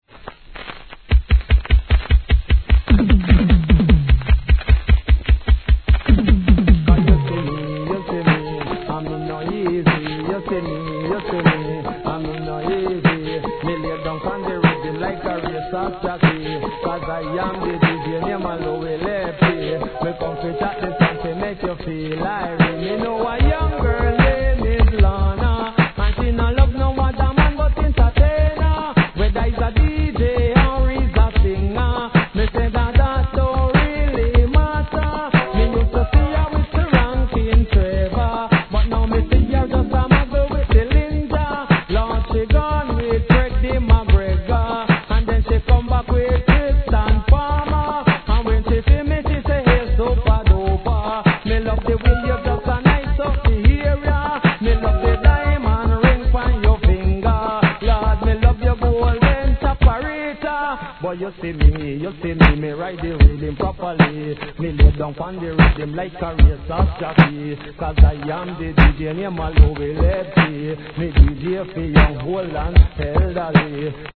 REGGAE
完全OUT OF KEYで突っ走るというよりマイペース。